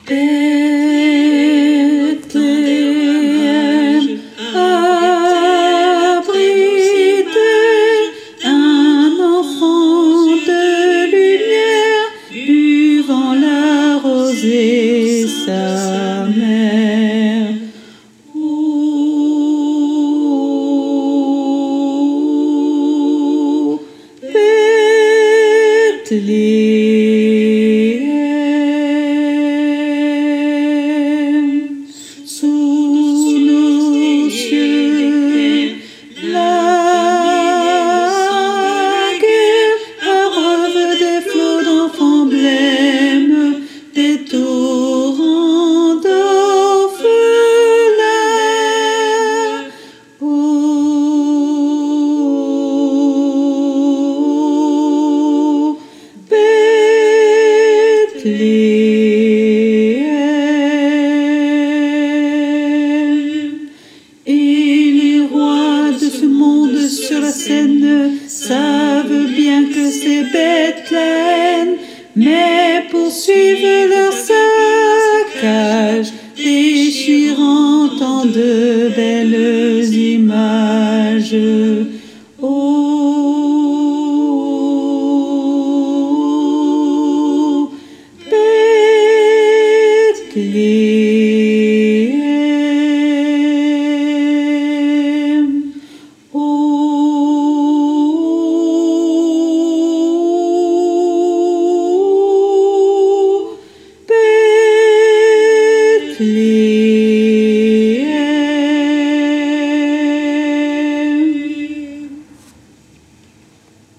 Mp3 versions chantées
Hommes Et Autres Voix En Arriere Plan